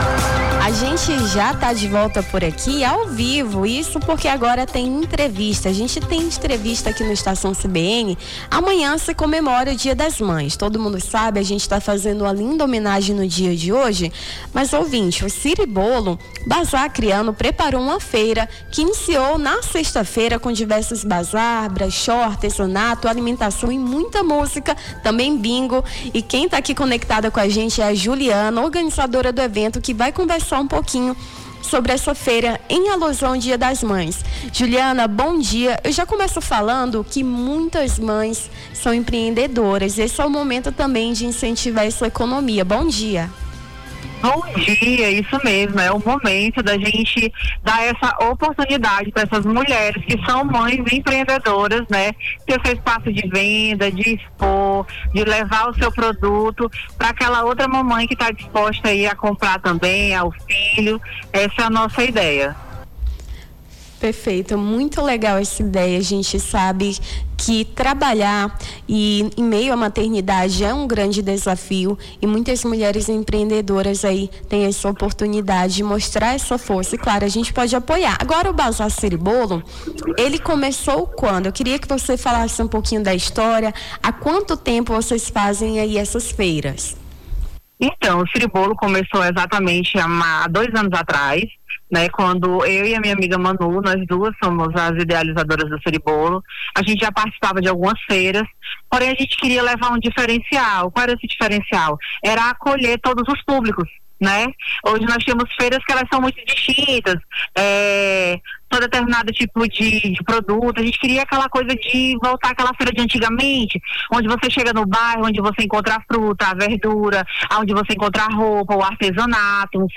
Nome do Artista - CENSURA - ENTR - BAZAR SIRIBOLO DIA DAS MÃES - 10.05.2025.mp3